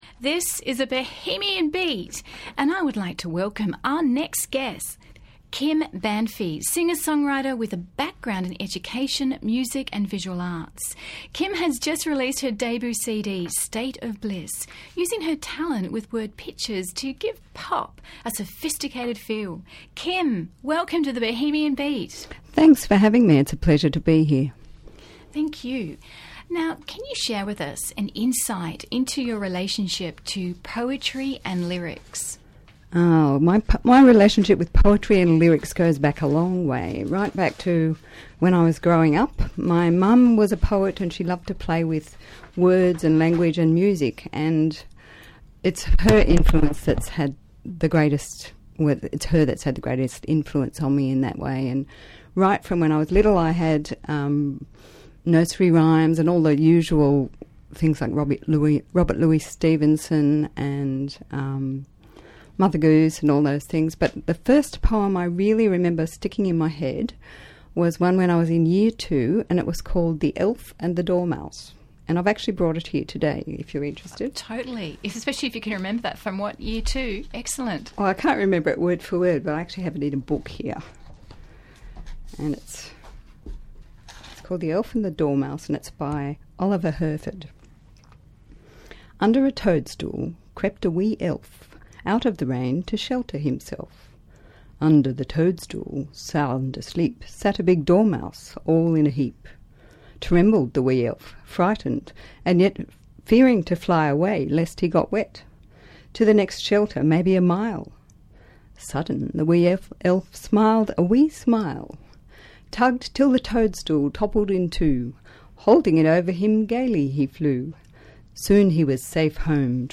Bohemian Beat interview